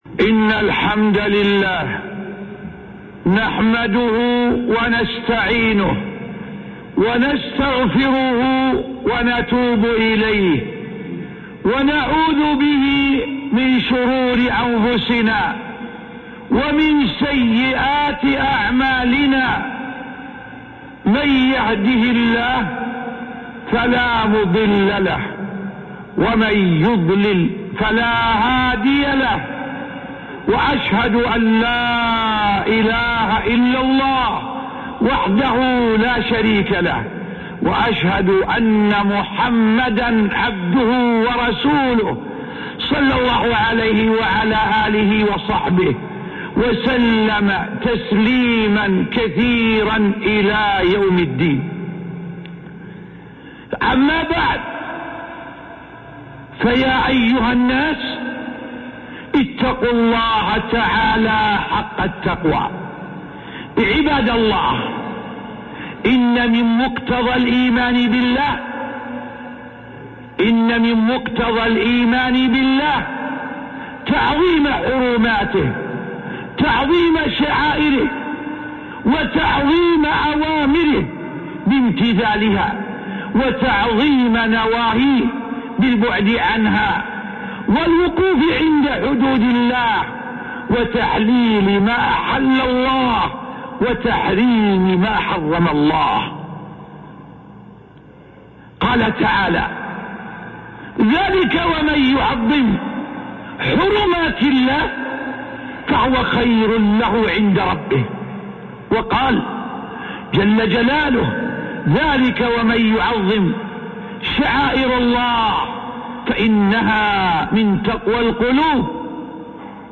خطبة من علامات تعظيم أوامر الله عزوجل الشيخ عبد العزيز بن عبد الله آل الشيخ